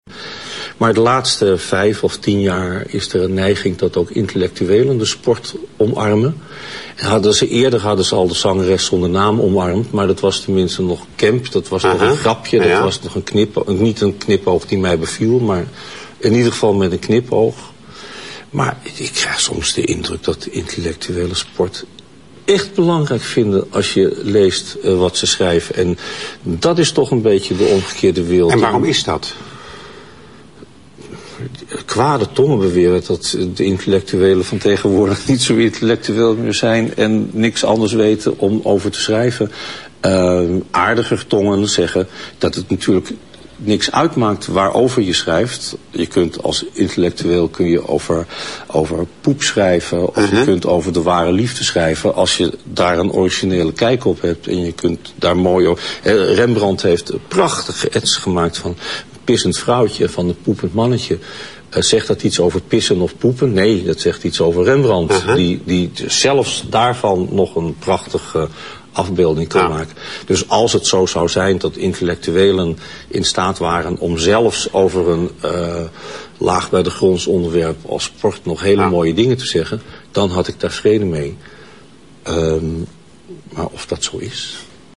Boeken&cetera is weer terug op TV, en biedt nog altijd niet meer dan radio met pratende hoofdjes.
Vandaag te gast was Midas Dekkers.